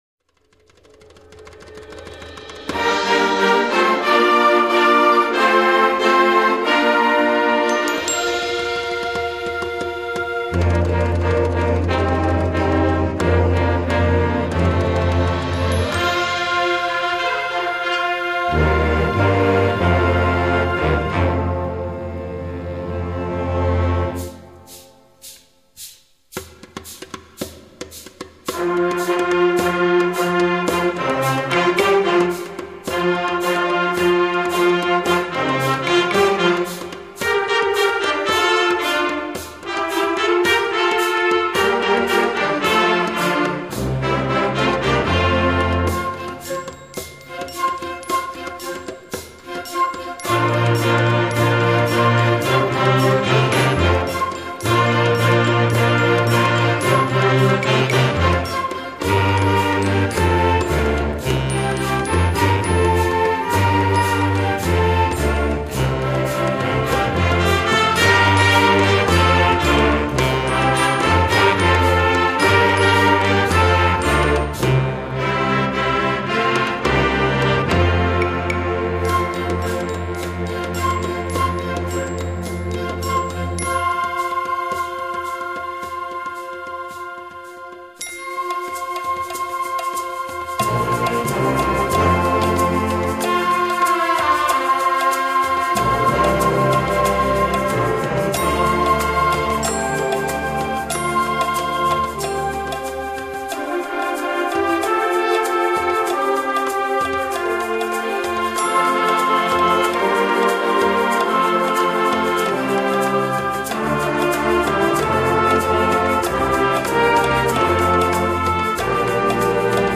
S C H O O L   C O N C E R T   B A N D S